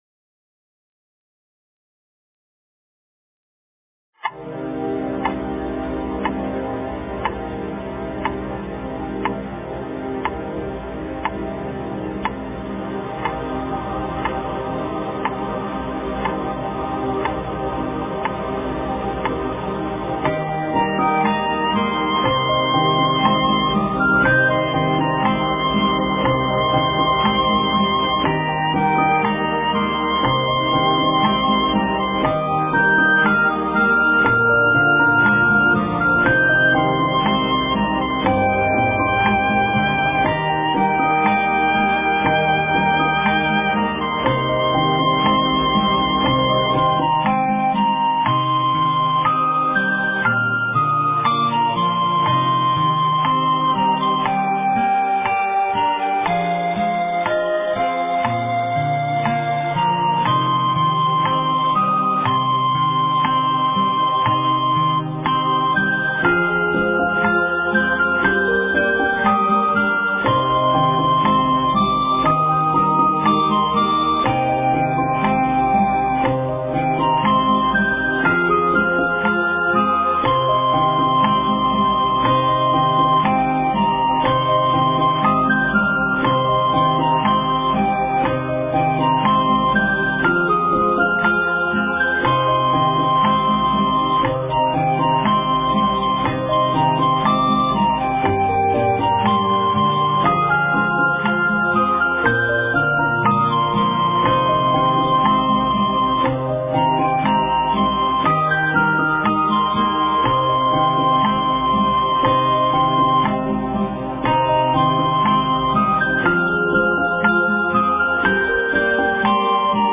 心经 诵经 心经--佚名 点我： 标签: 佛音 诵经 佛教音乐 返回列表 上一篇： 大悲咒 下一篇： 观世音菩萨发愿偈 相关文章 印光法师文钞11 印光法师文钞11--净界法师...